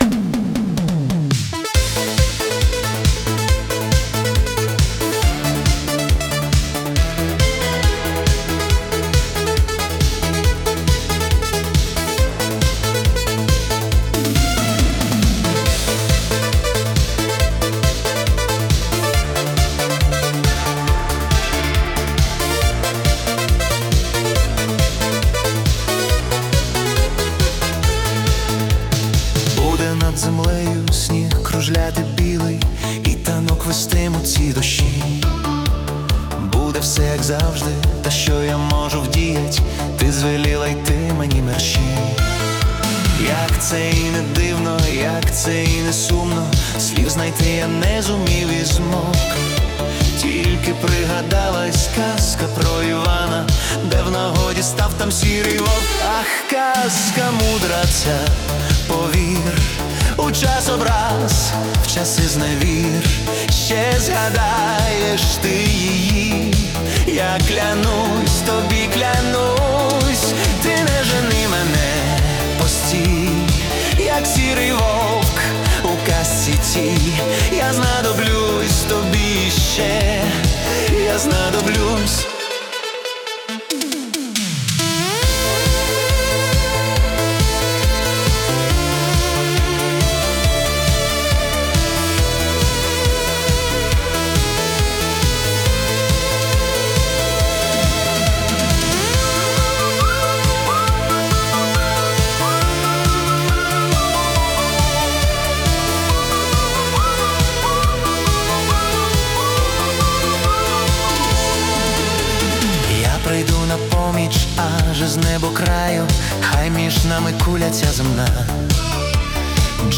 Italo Disco / Synth-pop
Ретро-звучання надії